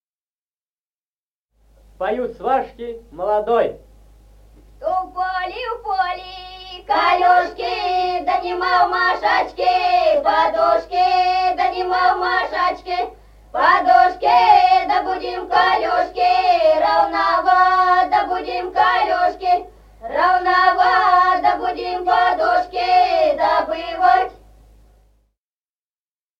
Музыкальный фольклор села Мишковка «У поле, в поле калюжки», свадебная.